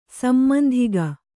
♪ sammandhiga